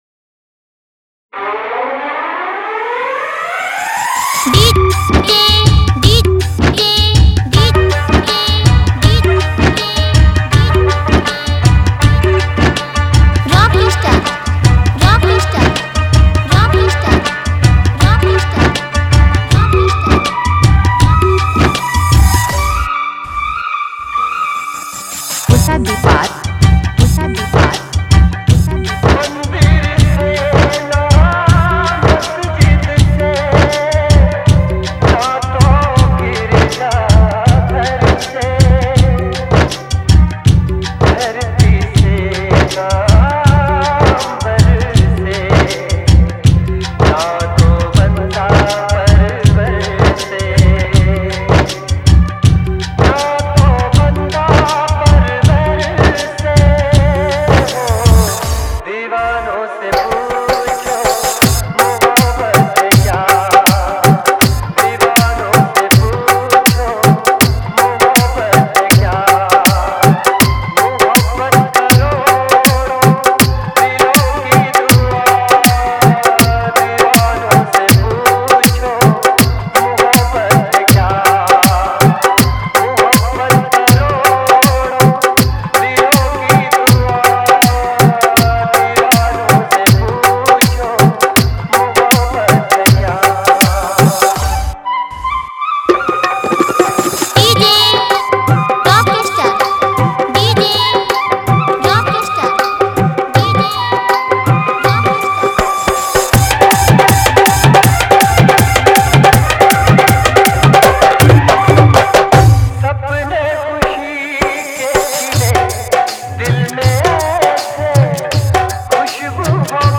Category:  Love Dj Remix